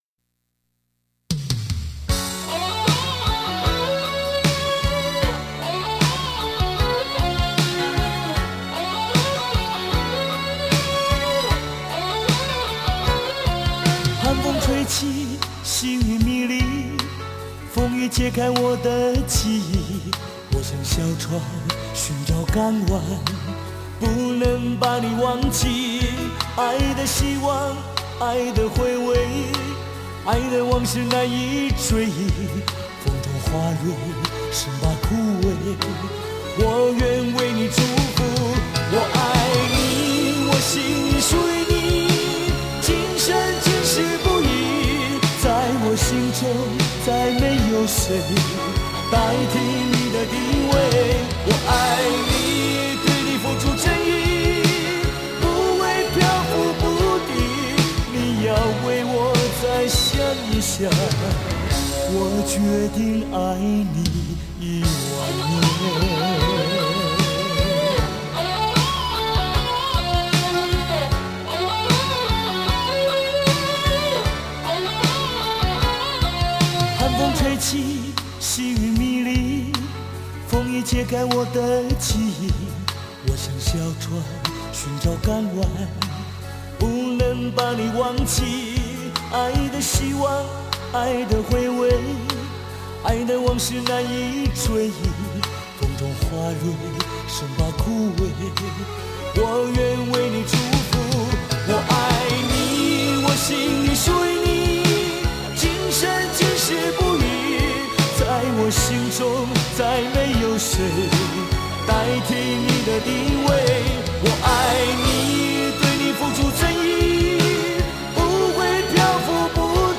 品尝最纯正的情歌滋味
感受李式歌声如清流般透彻